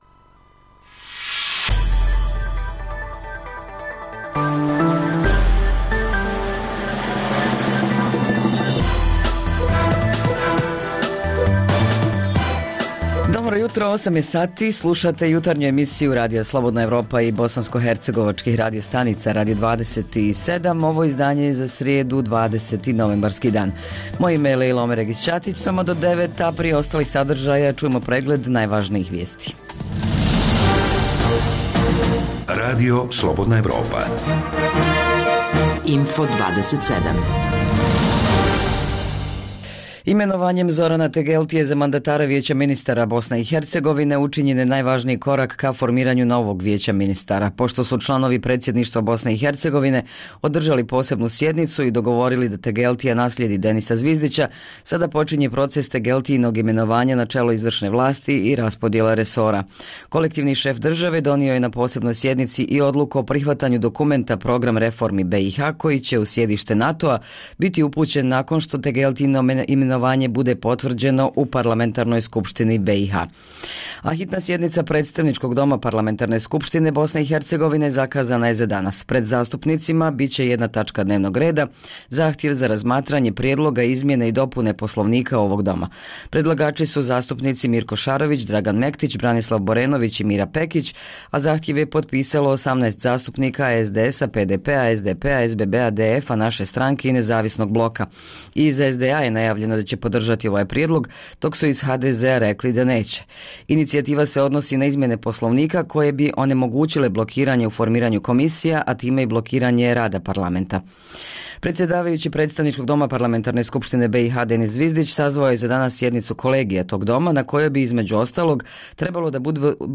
O tome više u javljanju naše dopisnice iz tog grada.